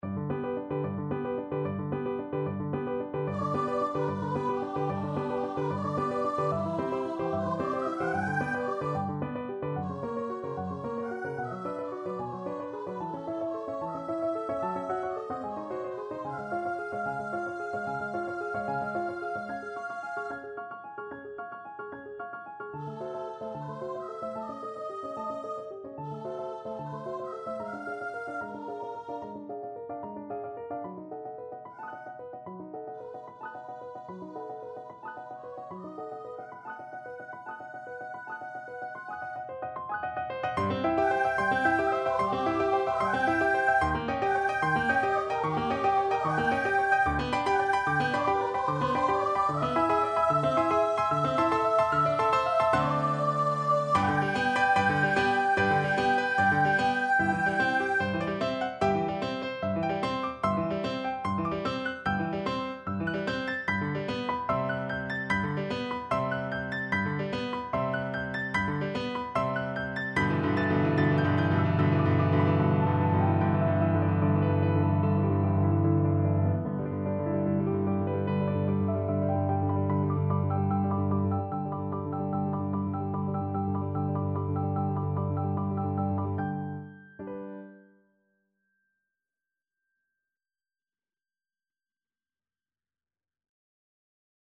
Soprano Voice
G major (Sounding Pitch) (View more G major Music for Soprano Voice )
4/8 (View more 4/8 Music)
~ =74 Sehr lebhaft, jubeld
Classical (View more Classical Soprano Voice Music)